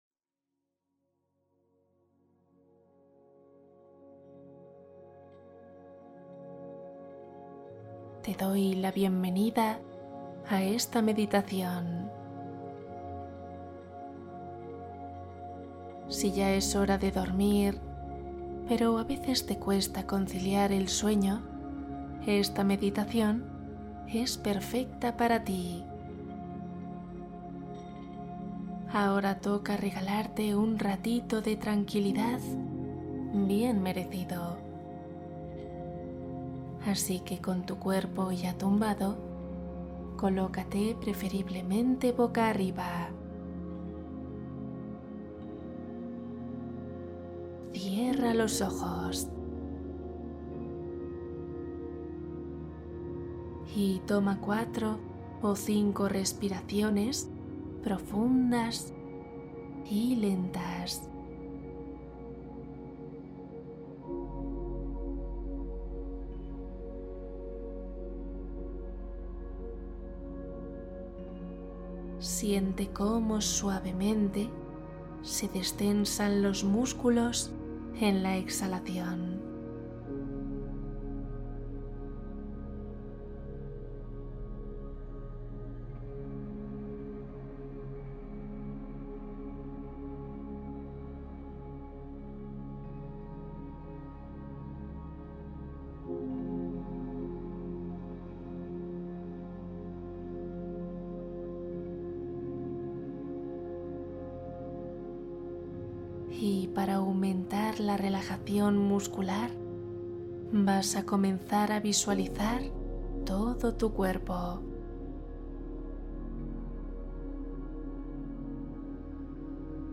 Meditación matutina 12 minutos para empezar el día en paz